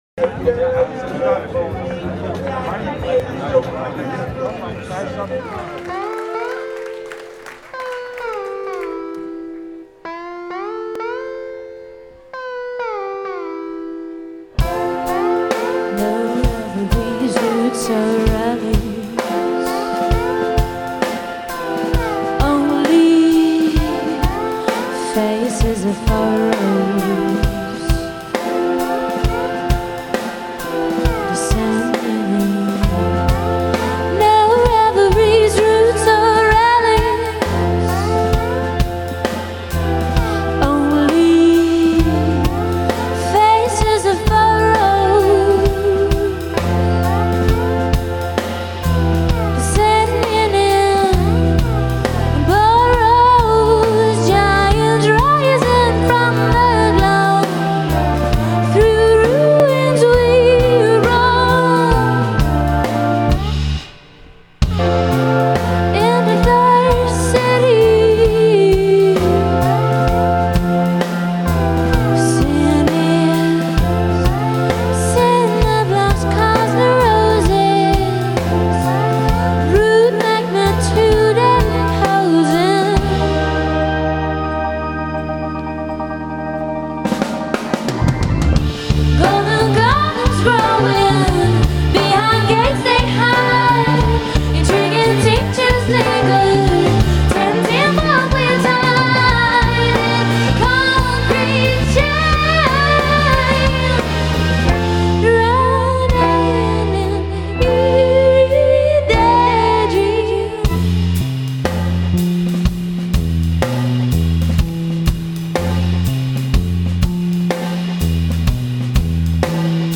A voice of dreams and other worlds.
Psych Groove Poppers